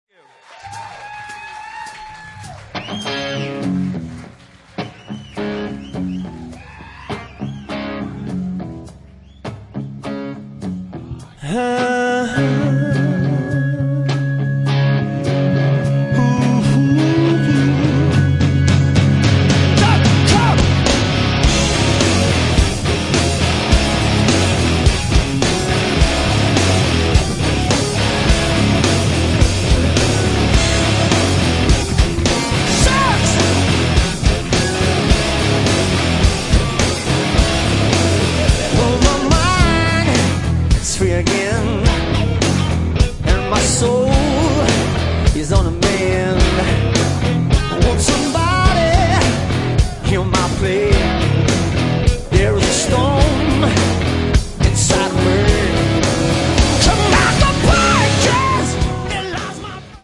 Рок
Двойной концертник рок-легенды.